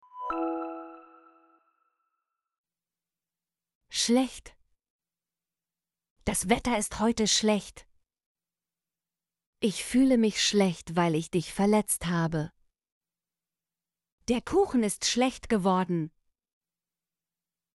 schlecht - Example Sentences & Pronunciation, German Frequency List